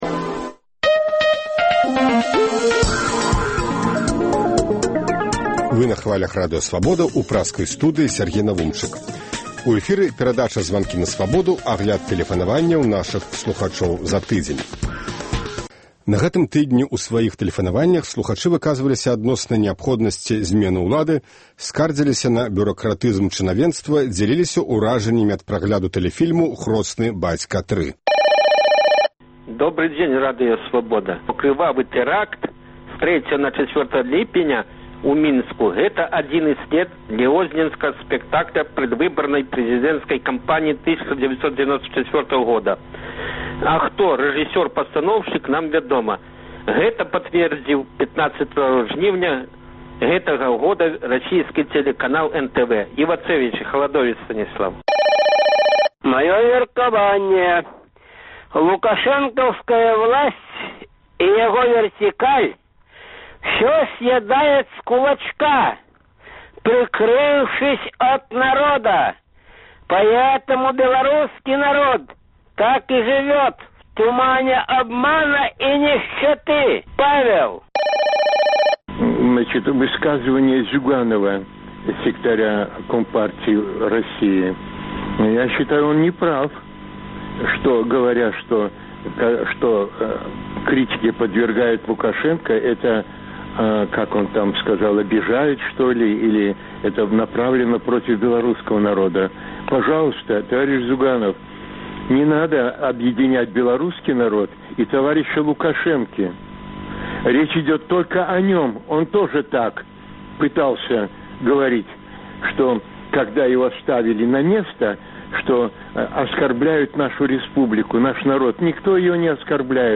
Агляд тэлефанаваньняў за тыдзень